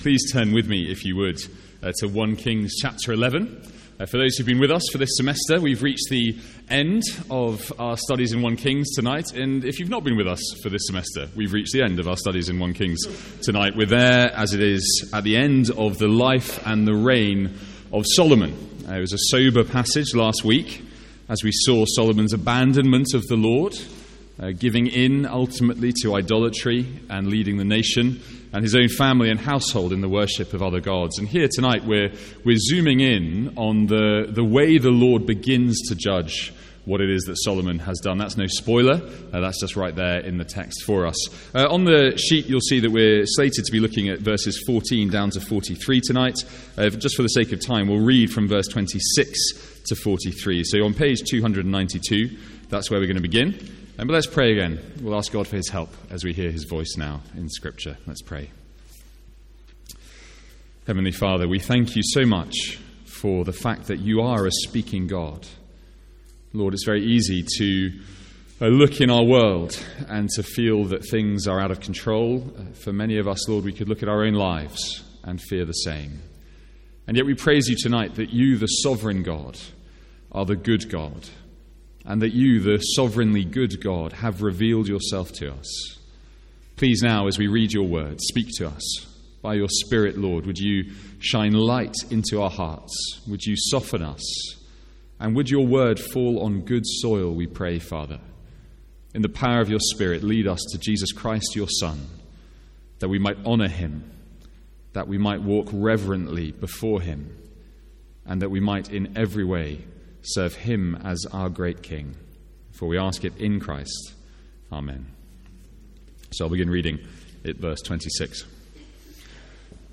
Sermons | St Andrews Free Church
From our evening series in 1 Kings.